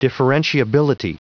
Prononciation du mot differentiability en anglais (fichier audio)
Prononciation du mot : differentiability